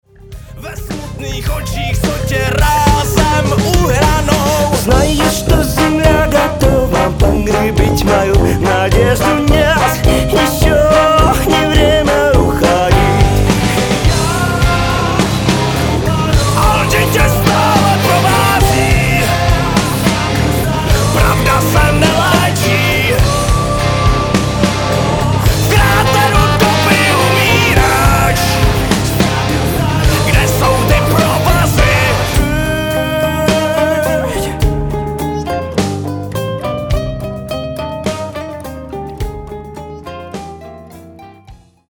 Zakomponování trombonu a tuby mě dostalo.